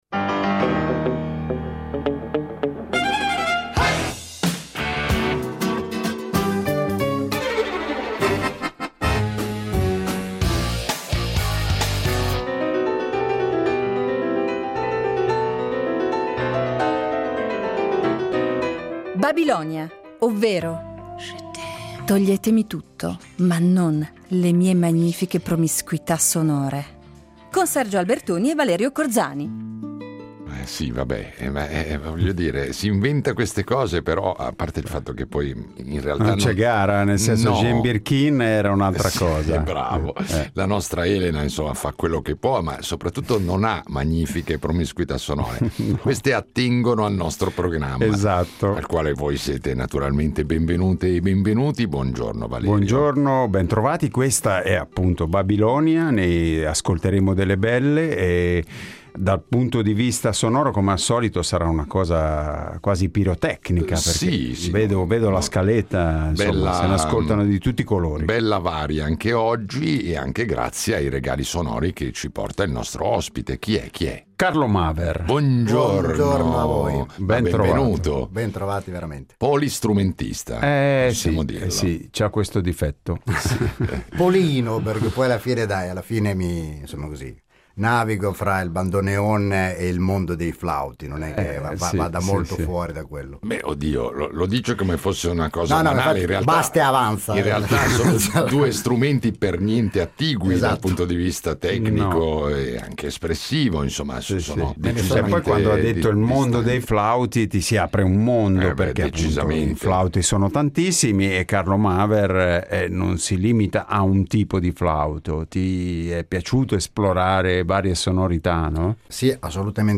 Flautista, bandoneonista e compositore